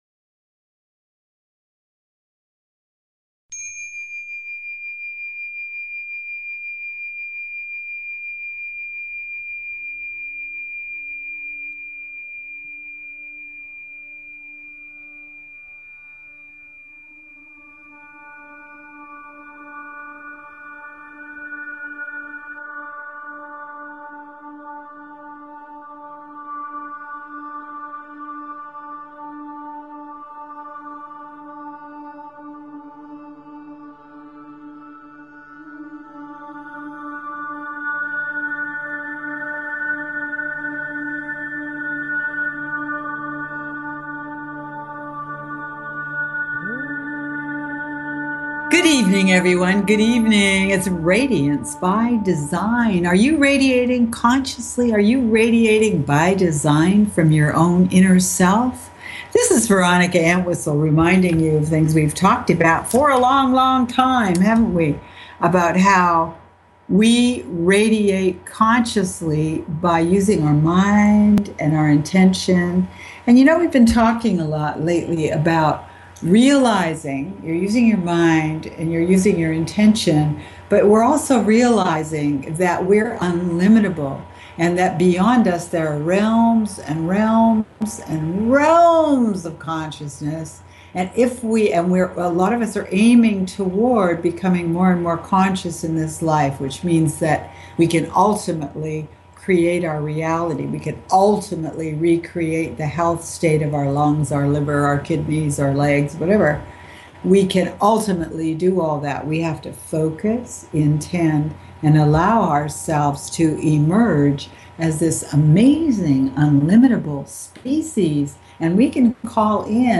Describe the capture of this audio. Radiance By Design is specifically tailored to the energies of each week and your calls dictate our on air discussions. Together we explore multi-dimensional realities, healing through energy structures, chakra tuning, the complexities